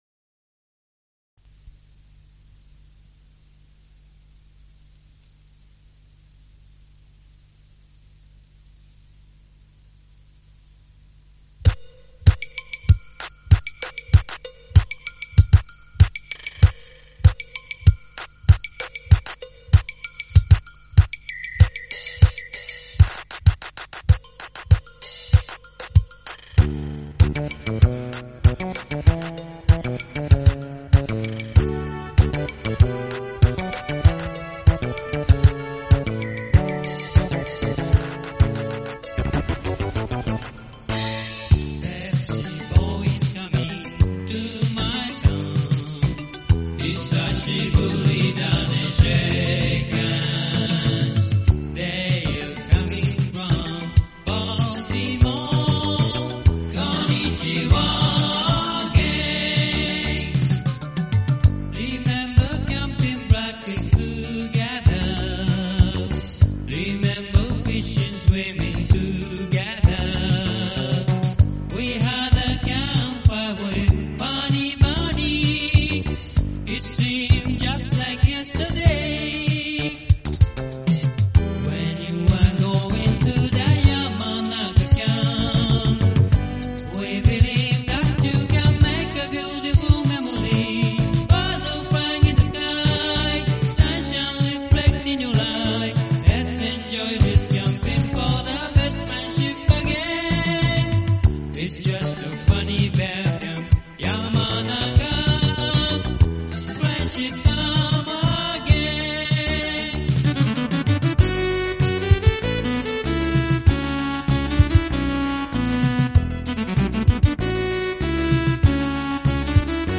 This lively tune was inspired by the playful spirit of the Baltimore Scouts, who often slipped away from their leaders during camp, bubbling with energy like a can of Pepsi.